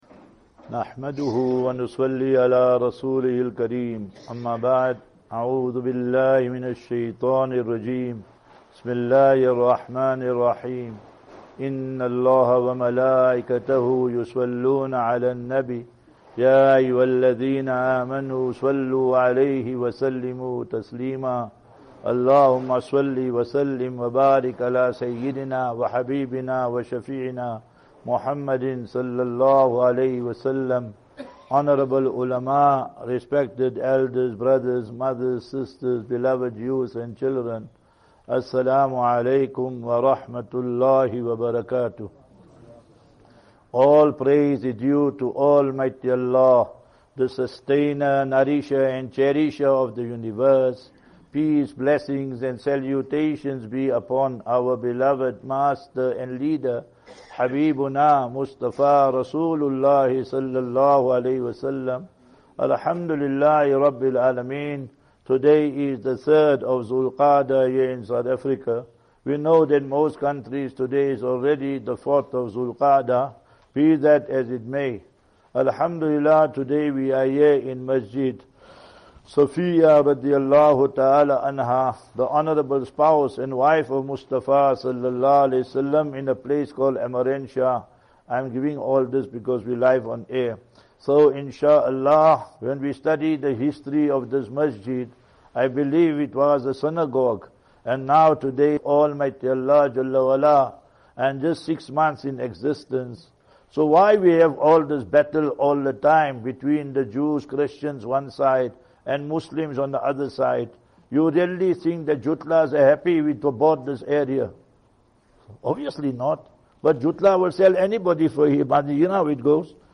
2 May 02 May 2025 - Jumu'ah Lecture MASJID SAFIYYAH - EMMARENTIA